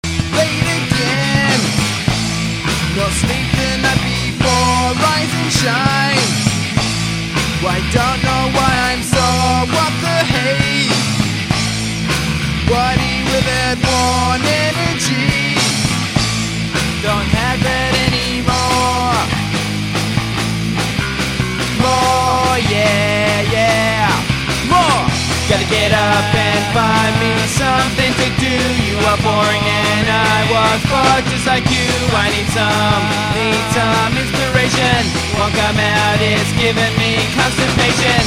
Good quality and recorded by digital multitrack.